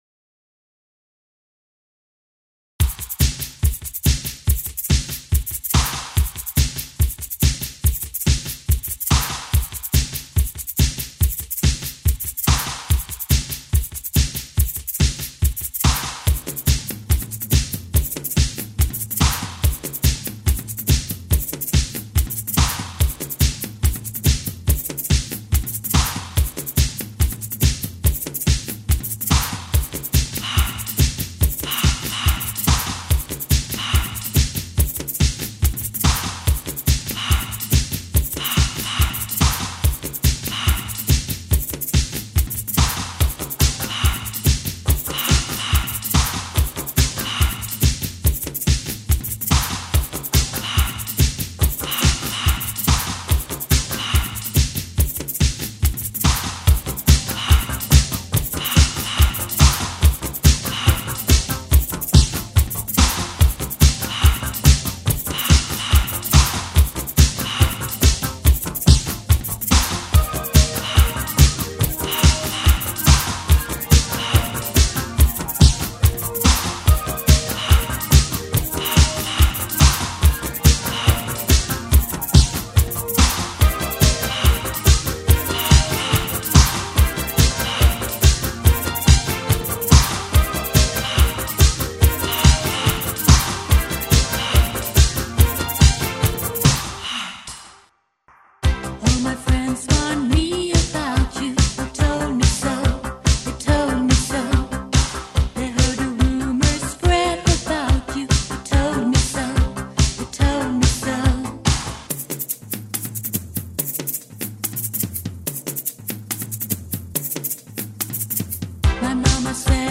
disco music
both sexy and infectious